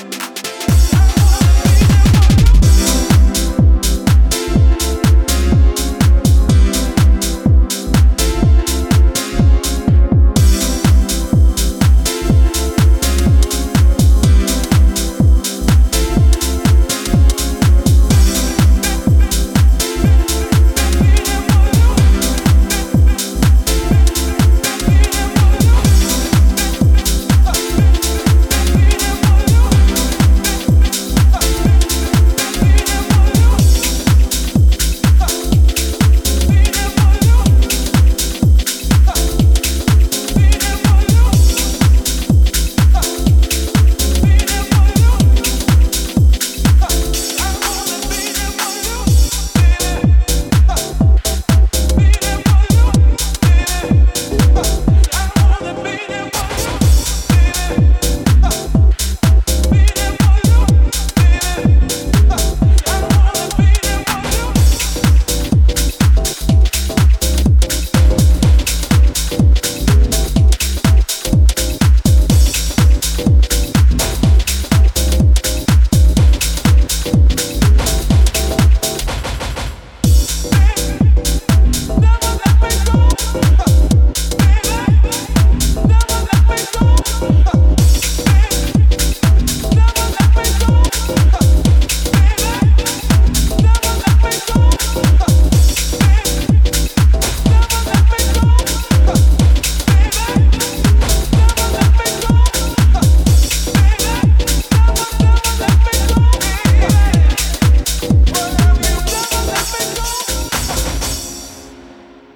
絢爛なピアノとソウルフルなヴォイス・サンプルがウォームでオーセンティックな魅力を放つディープ・ハウス